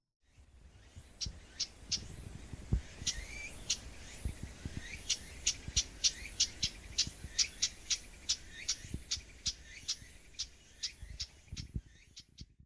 • ほか動物の声（屋久島にて収録）
ウグイス　地鳴き